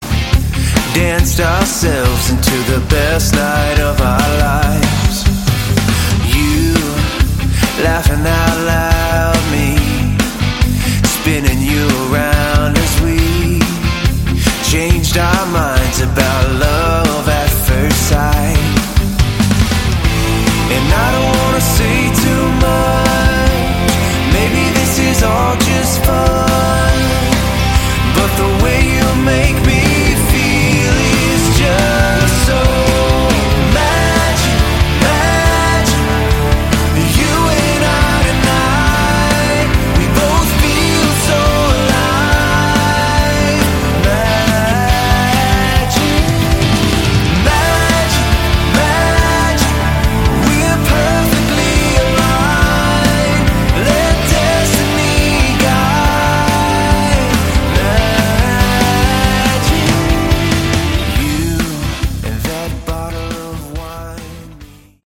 Category: Melodic Rock
Guitars, Keyboards